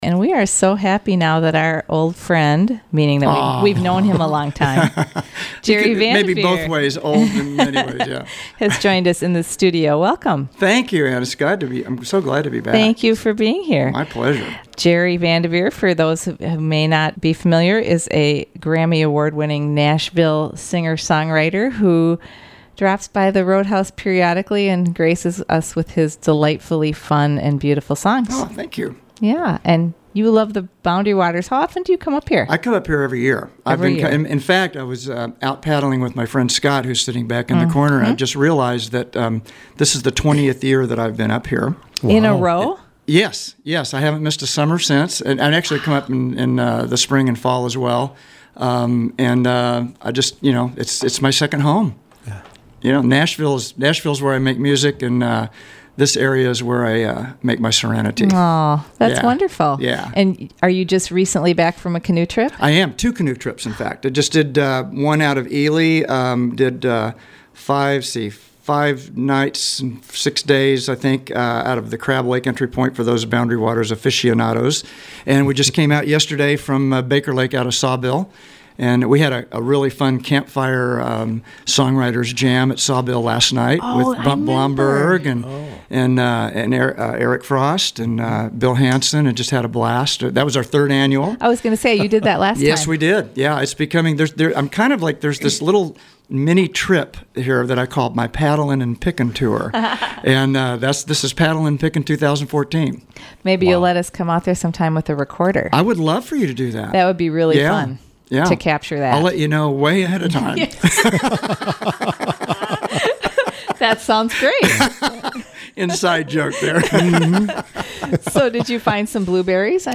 guitar
paddling music
Live Music Archive